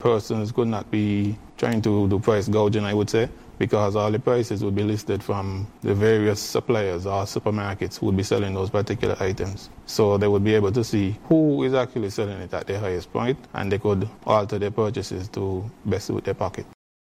That was Paul Queeley , Director of the St. Kitts Department  of Consumer Affairs speaking about the new SKN Smart Shopper mobile app and website set to launch on Wednesday, February 18th, 2026, marking a major step in consumer protection and digital transformation.